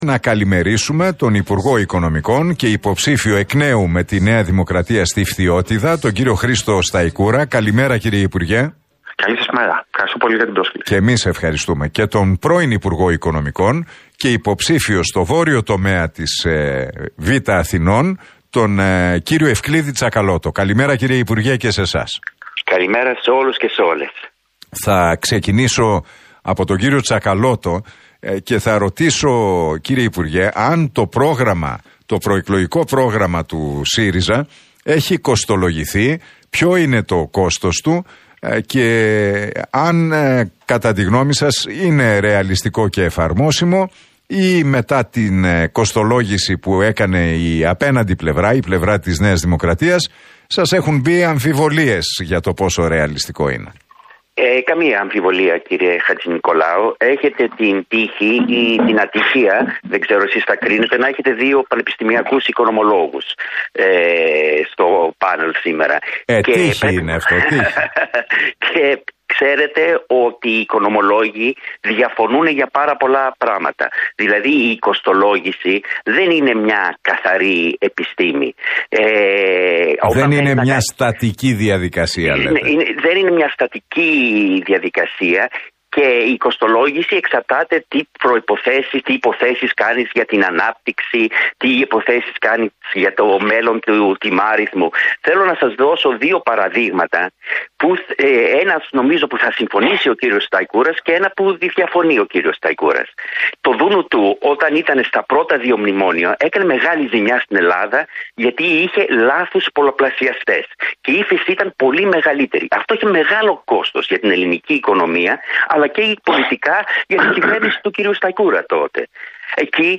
Εκλογές 2023: Debate Σταϊκούρα - Τσακαλώτου στον Realfm 97,8
Τα ξίφη τους διασταύρωσαν στον αέρα του Realfm 97,8 και την εκπομπή του Νίκου Χατζηνικολάου, σε ένα debate ο υπουργός Οικονομικών και υποψήφιος με τη ΝΔ,